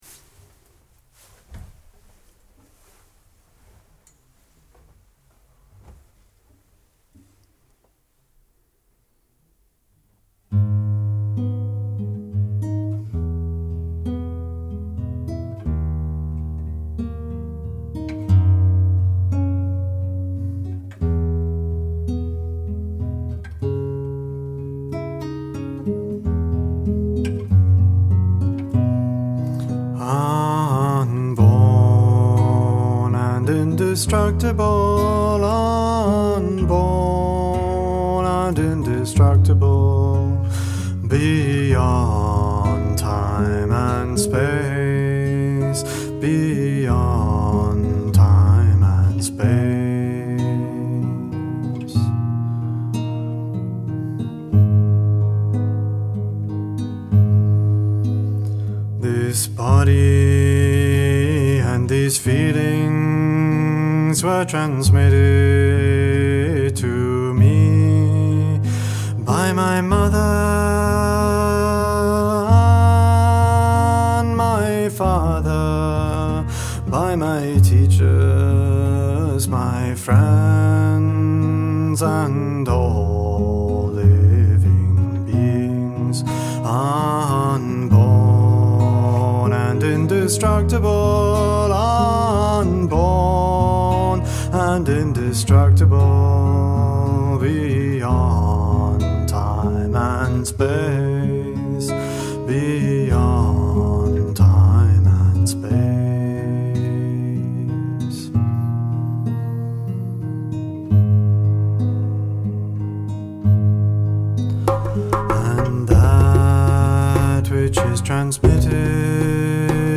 Chants & Songs